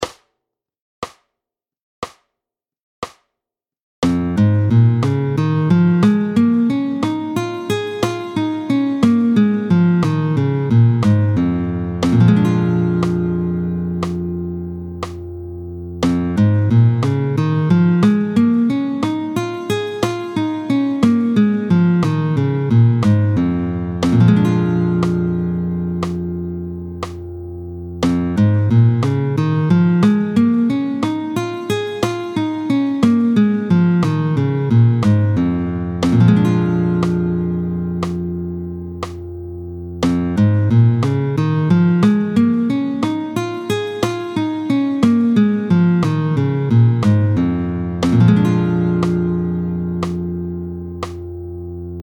31-04 Doigté 4 en Sib, tempo 60